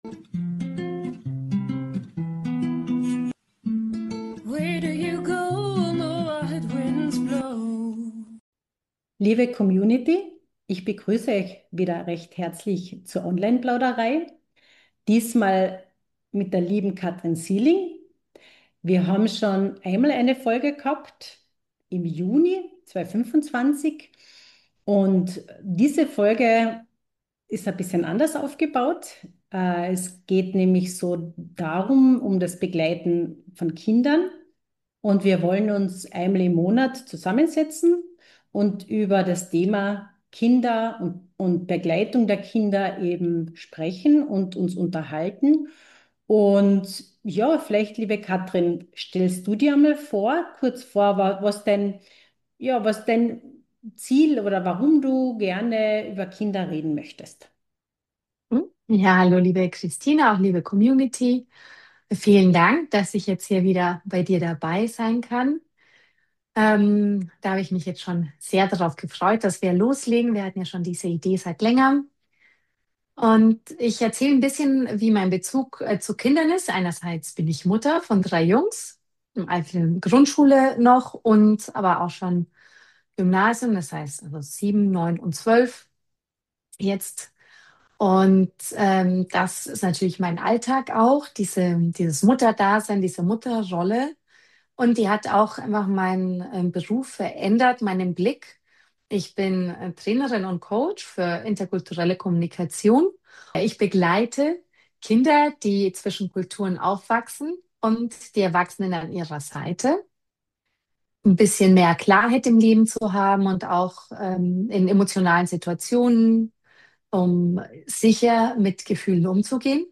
Online Plauderei - ehrliche Gespräche über das Begleiten von Kindern und Jugendlichen, voller Herz, Erfahrung und neuer Perspektiven.